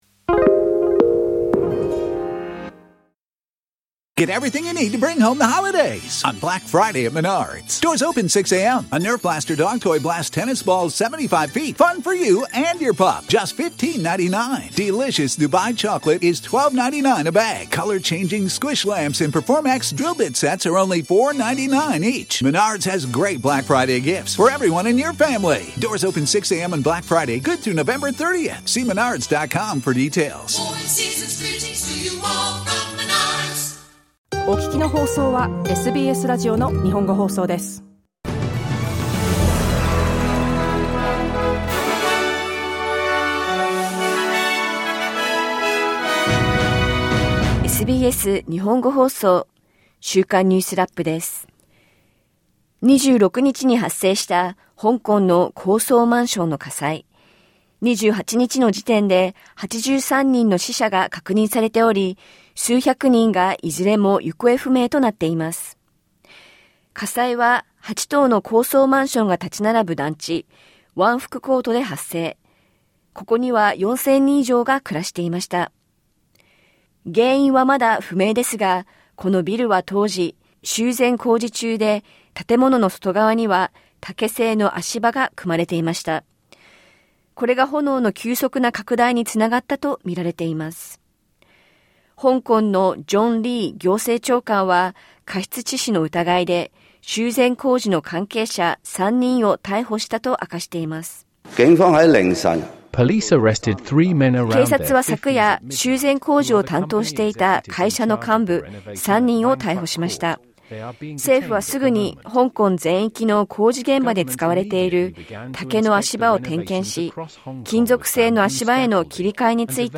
SBS日本語放送週間ニュースラップ 11月28日金曜日
ロシアのプーチン大統領が、提示されているウクライナ和平案についてコメントしました。1週間を振り返る週間ニュースラップです。